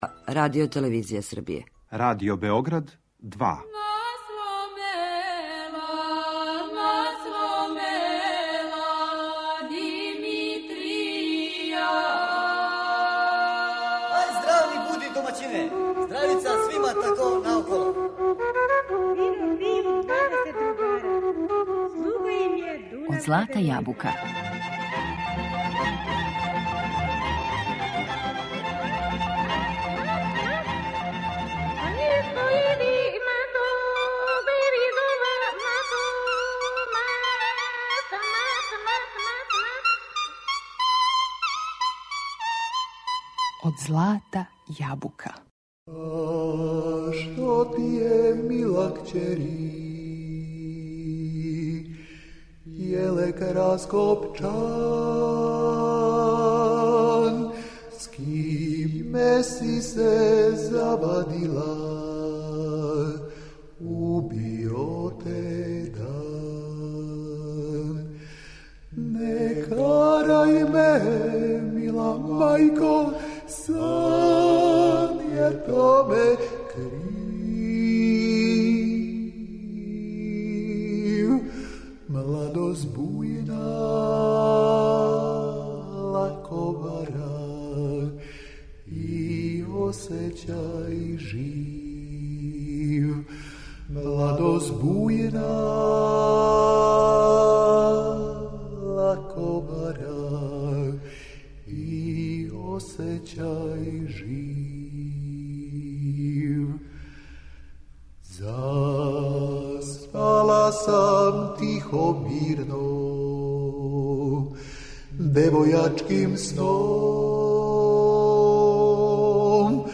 Гости данашње емисије Од злата јабука су Ратко и Радиша Теофиловић. Разговарамо о новом музичком материјалу и промоцији албума.